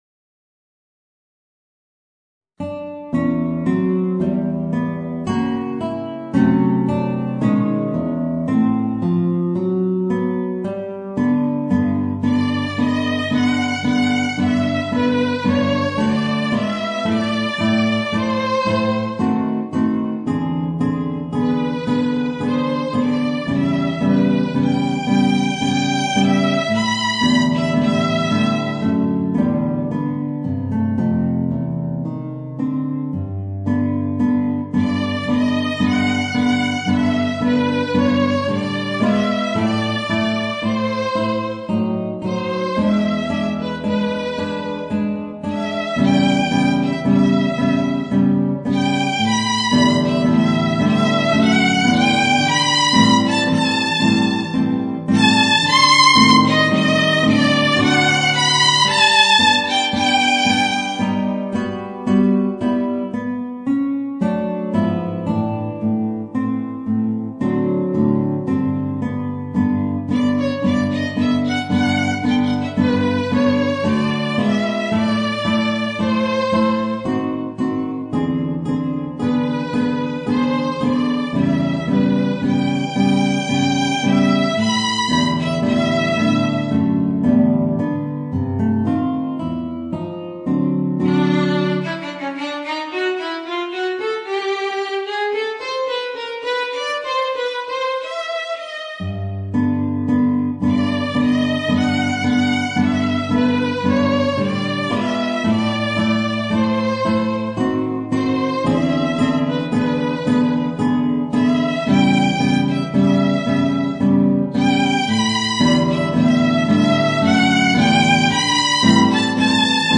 Voicing: Guitar and Violin